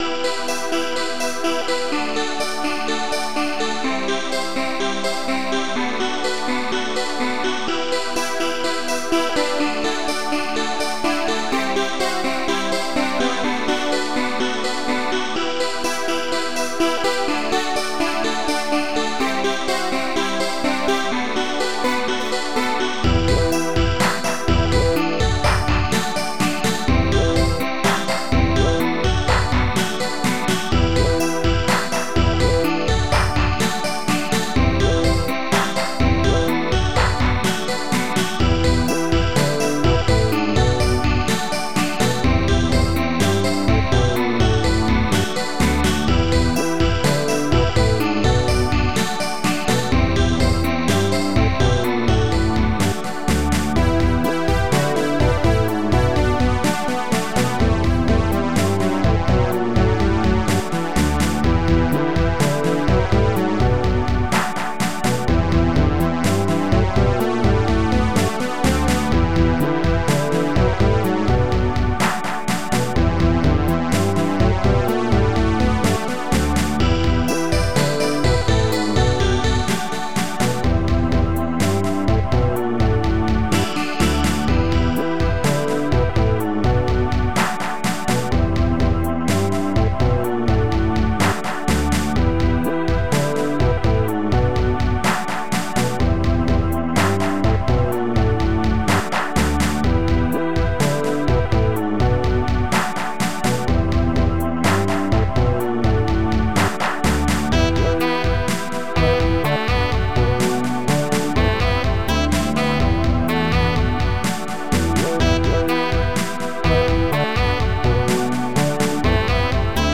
Protracker and family